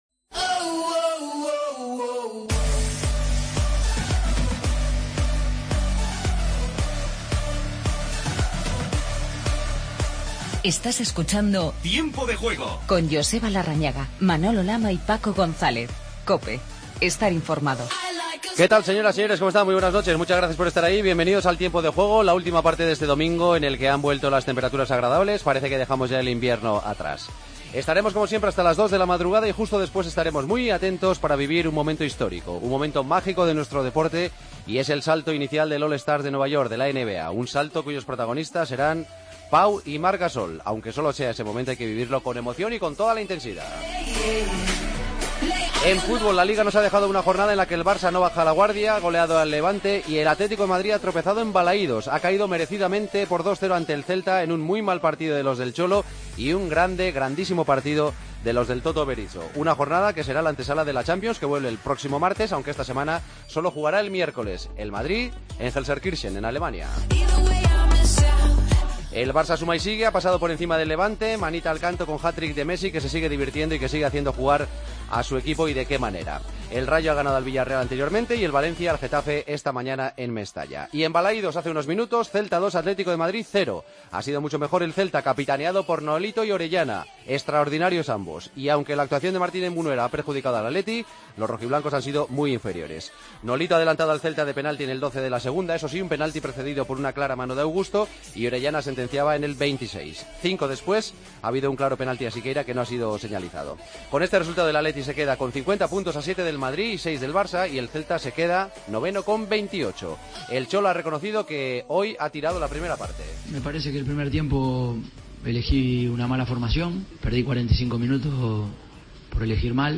Paso por Balaídos para hablar con los protagonistas.
Escuchamos a Bartra en rueda de prensa.